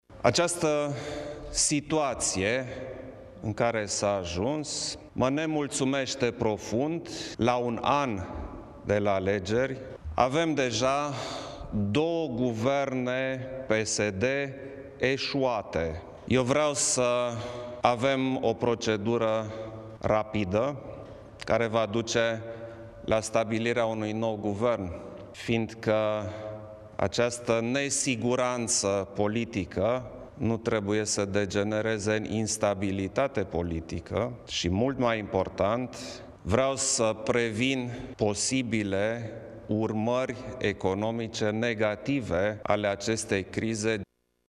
Președintele a precizat că această nesiguranţă politică nu trebuie să degenereze în instabilitate politică, subliniind că dorește să prevină posibile urmări economice negative ale crizei, generată, iarăşi, în interiorul PSD-ului: